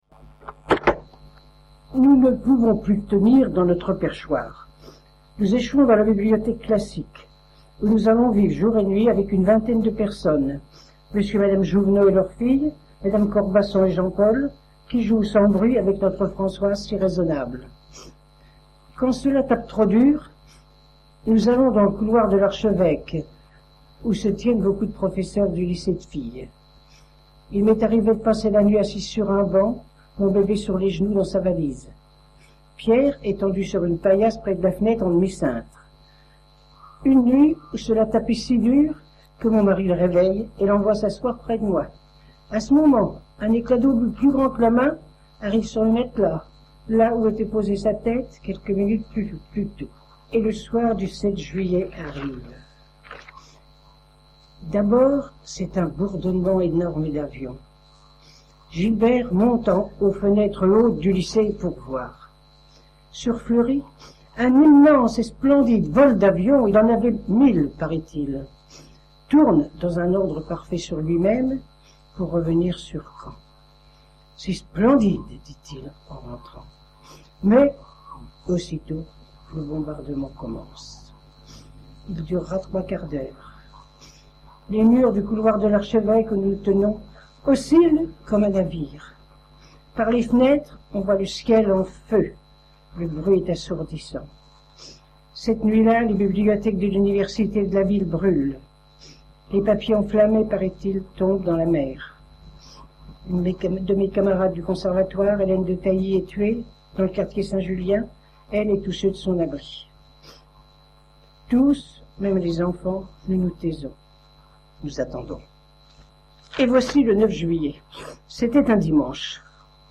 Cette interview est issue du fonds d'enregistrements de témoignages oraux relatifs à la Seconde Guerre mondiale conservés et archivés au Mémorial de Caen, que le musée a gracieusement mis à la disposition de la Maison de la Recherche en Sciences Humaines dans le cadre du projet de recherche Mémoires de Guerre.